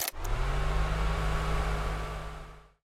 Sfx Fan Startwithbuttonpush Sound Effect
sfx-fan-startwithbuttonpush.mp3